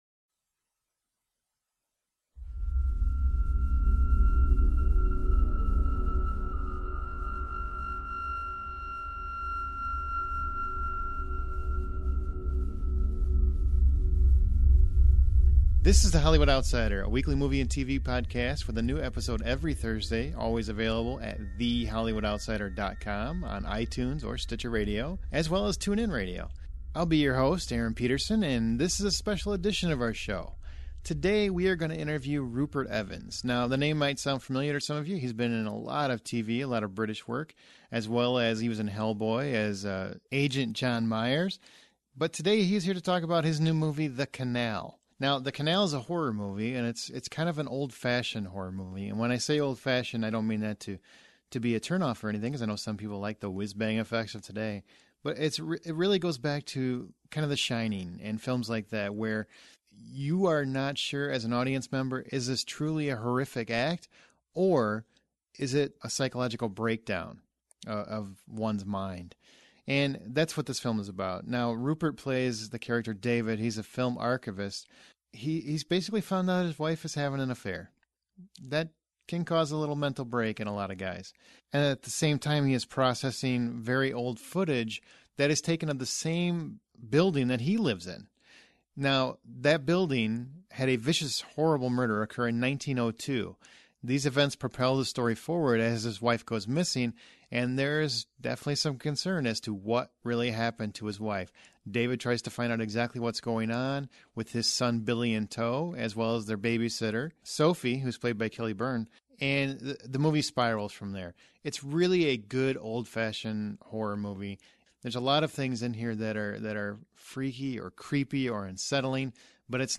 Actor Rupert Evans is our guest for this special episode of The Hollywood Outsider! The Canal, on VOD and at limited theaters on October 10th, stars Evans as David, a film archivist who discovers several murders took place at his home over a century ago.